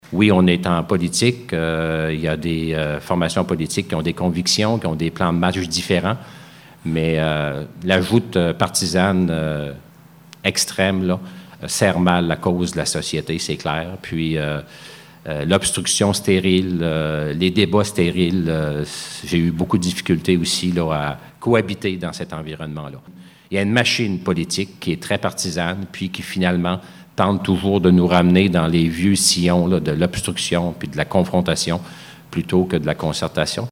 Arrivé en compagnie de sa femme sur le site de Berceau du Canada, le député de Gaspé s’est par la suite adressé à une trentaine de personnes, en conférence de presse.